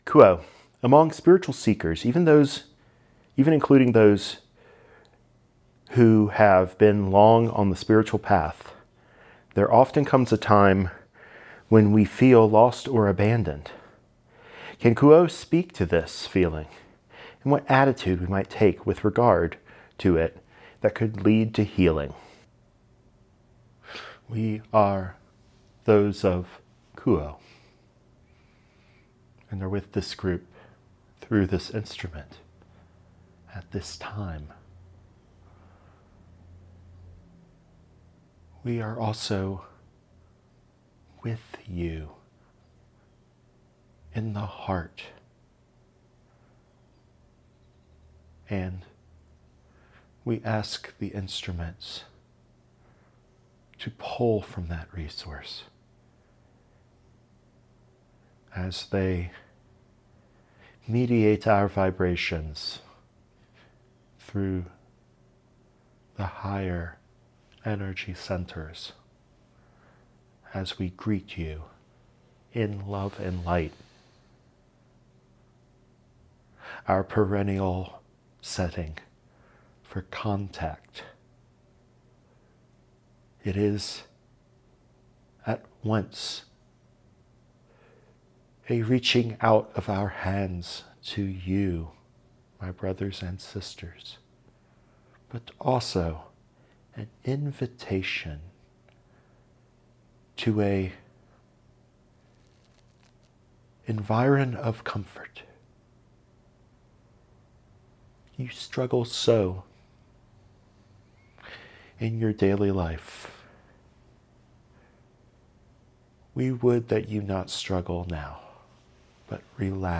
Channeled message